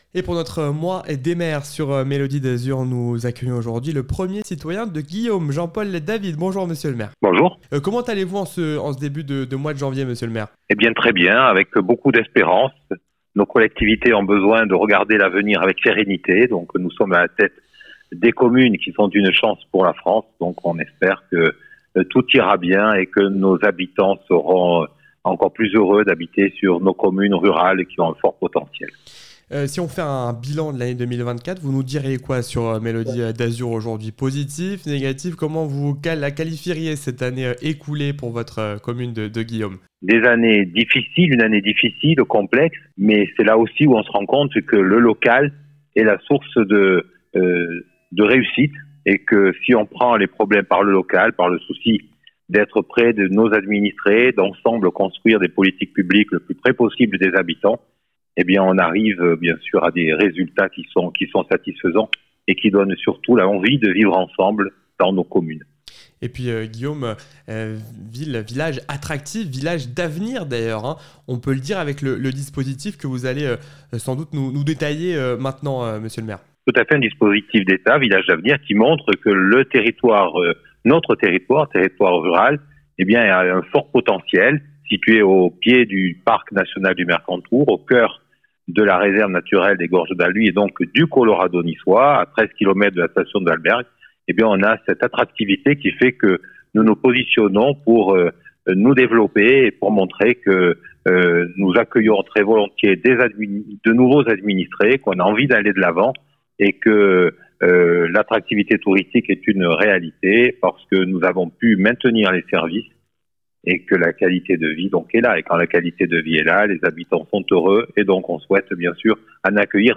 interview-des-maires-episode-3-guillaumes-avec-jean-paul-david.wav